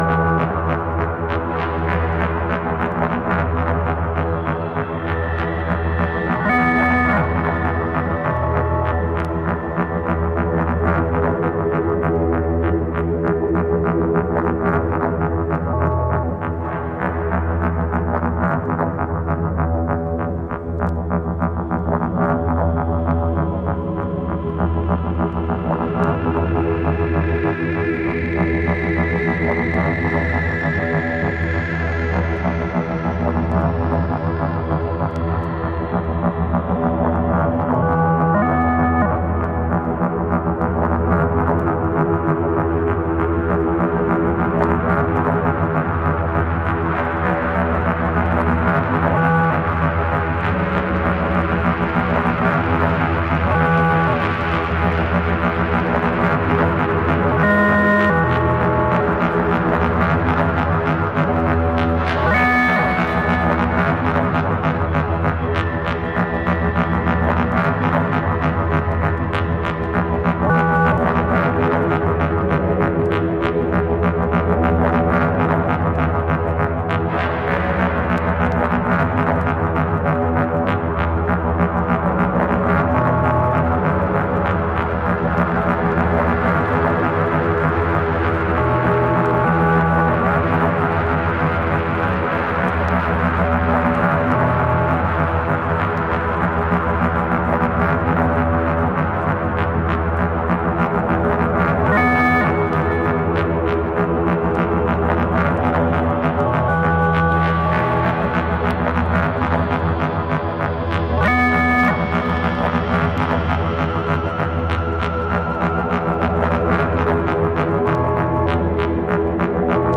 New School, Old school acid techno tracks!